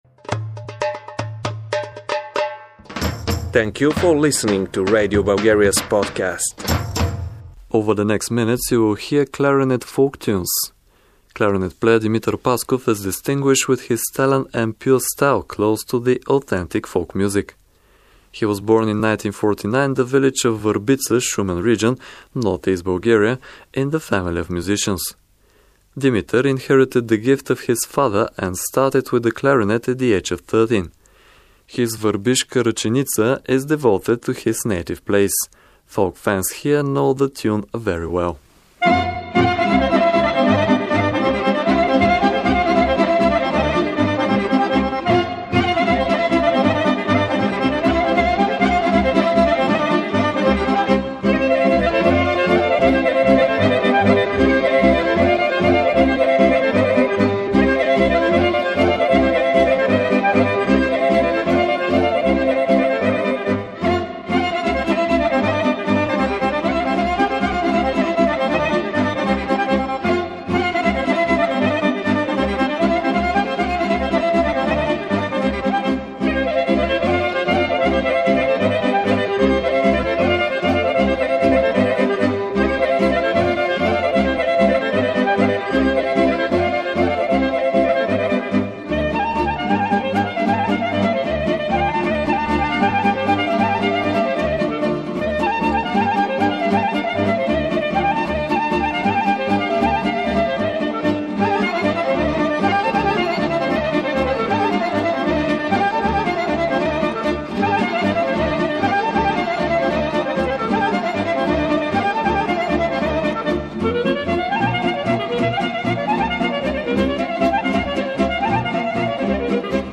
Clarinet
Over the next minutes you will hear clarinet folk tunes.